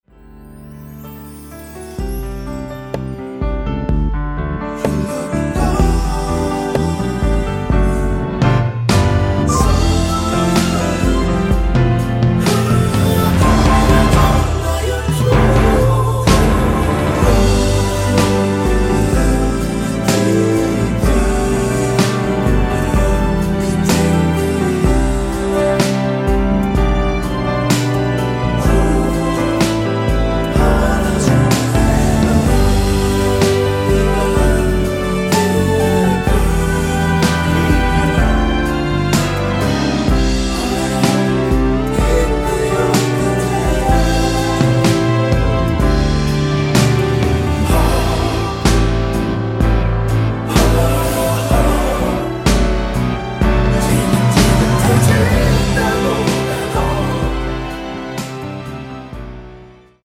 (-2) 내린 코러스 포함된 MR 입니다.(미리듣기 참조)
앞부분30초, 뒷부분30초씩 편집해서 올려 드리고 있습니다.
곡명 옆 (-1)은 반음 내림, (+1)은 반음 올림 입니다.